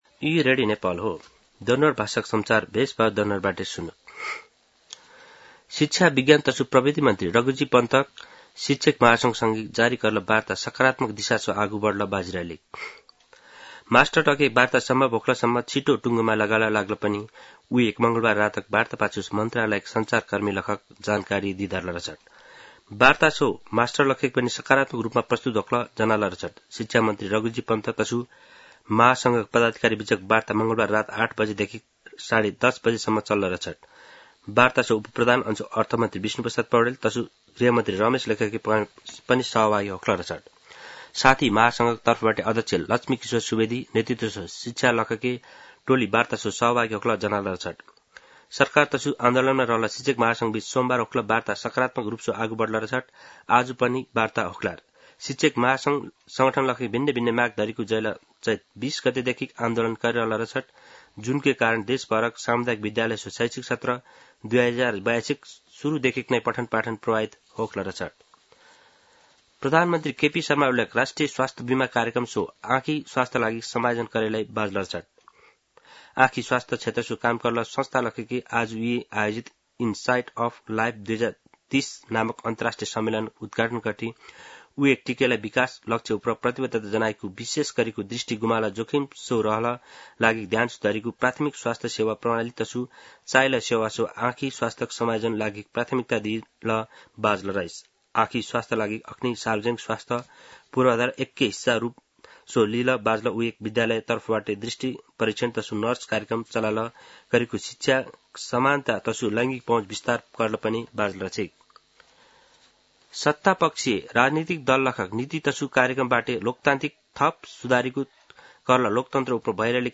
दनुवार भाषामा समाचार : १७ वैशाख , २०८२
danuwar-news-1-13.mp3